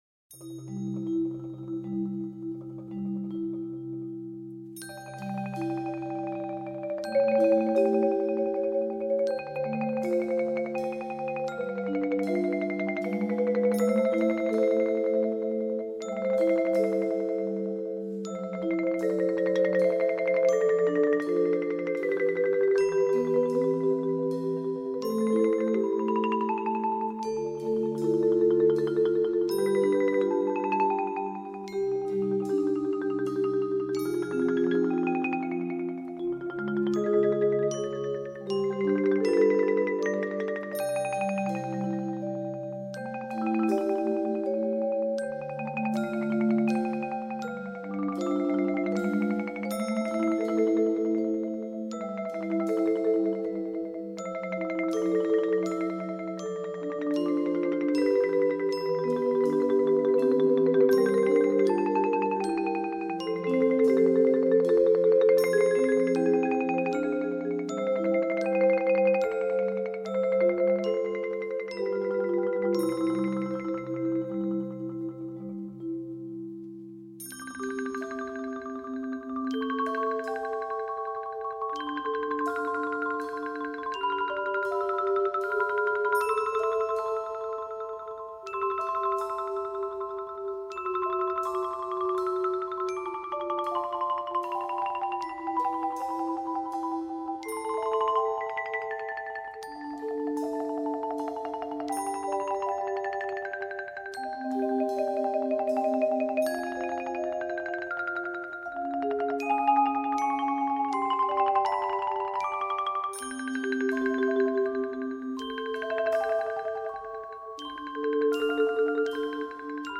Voicing: Mallet Choir